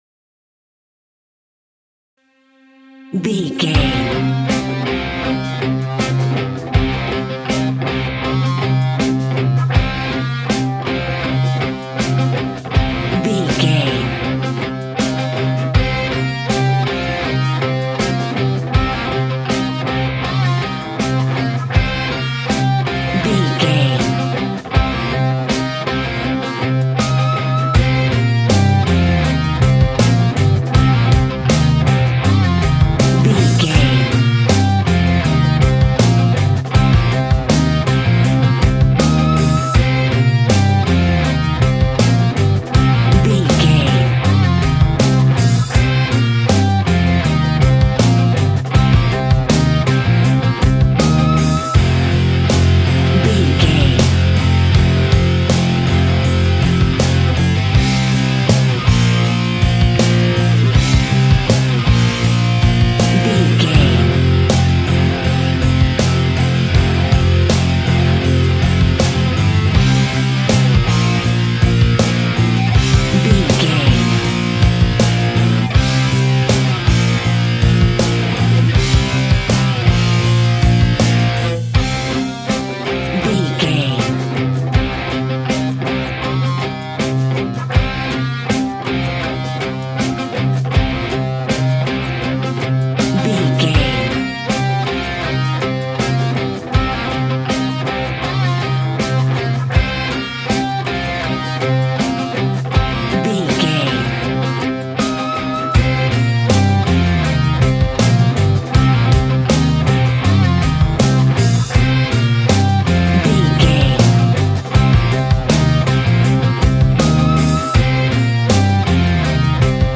Ionian/Major
hard
groovy
powerful
electric guitar
bass guitar
drums
organ